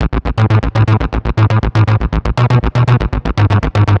TSNRG2 Bassline 003.wav